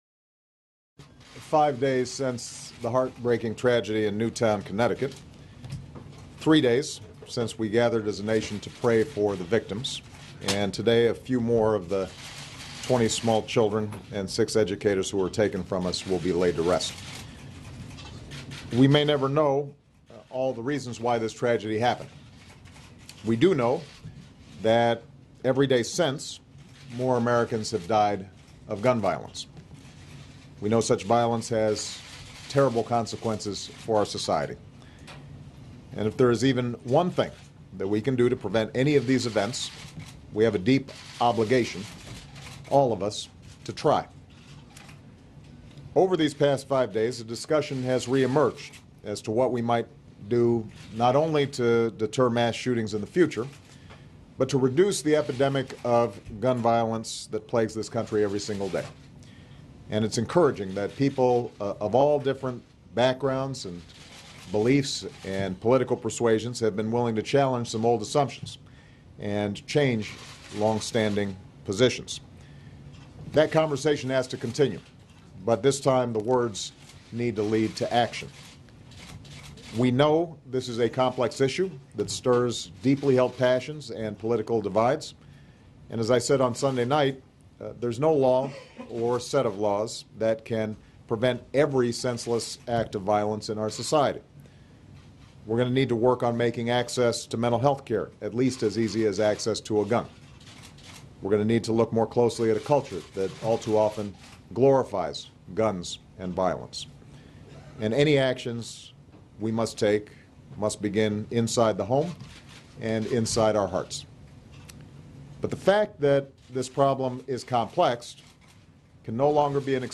In response to the elementary school shooting in Newtown, Connecticut, President Obama announces the formation of a task force on gun violence reduction to be led by Vice President Biden. Following his statement, the president answers questions from reporters, which were primarily about "fiscal cliff" negotiations and Speaker Boehner's "Plan B" proposal to avoid the fiscal cliff.
Broadcast on C-SPAN, Dec. 19, 2012.